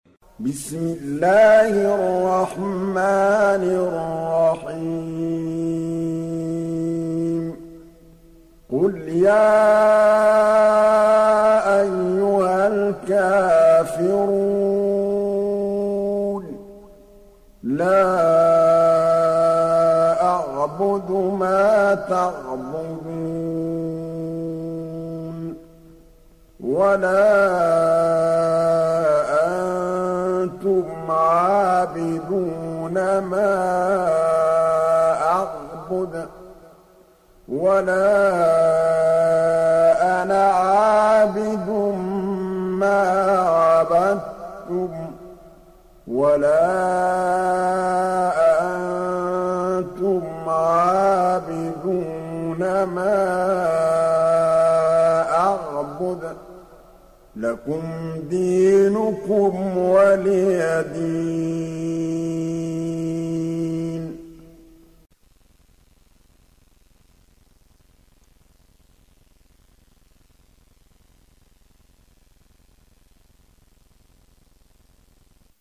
Kafirun Suresi İndir mp3 Muhammad Mahmood Al Tablawi Riwayat Hafs an Asim, Kurani indirin ve mp3 tam doğrudan bağlantılar dinle